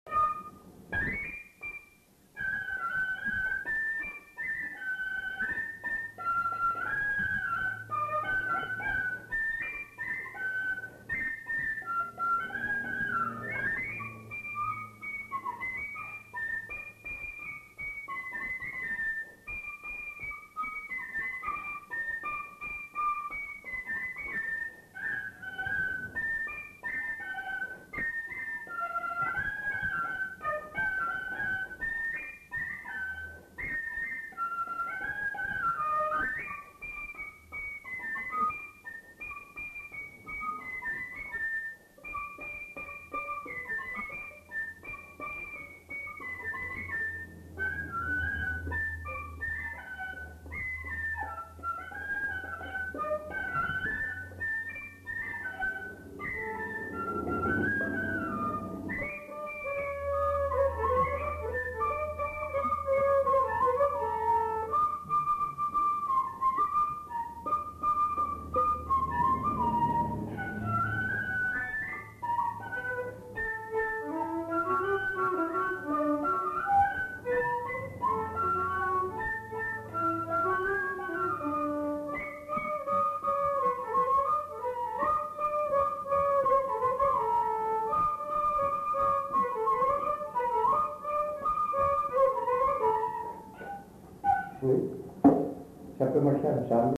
Aire culturelle : Bazadais
Lieu : Bazas
Genre : morceau instrumental
Instrument de musique : fifre ; violon
Danse : scottish